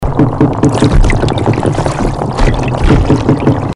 The red drum makes a drumming sound.
All drums are able to make a loud drumming or croaking sound by vibrating their swim bladder using special muscles.
red-drum-call.mp3